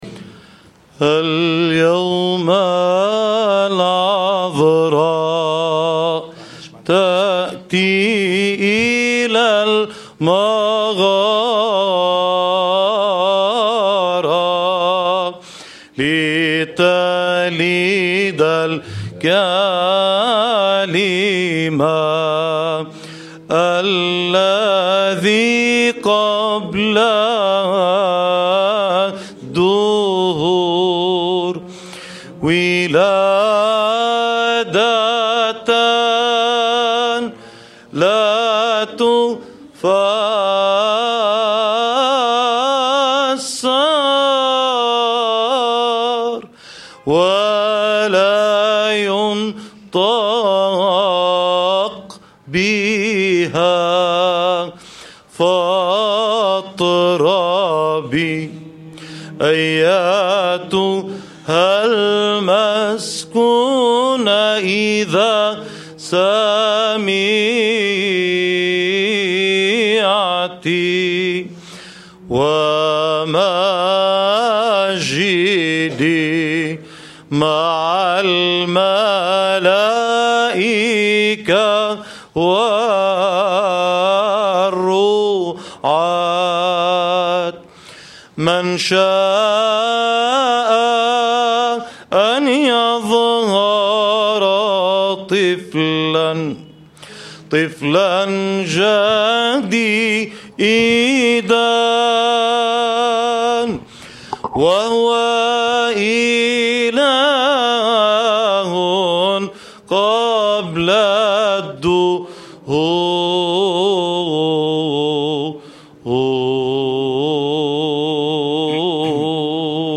تراتيل ميلادية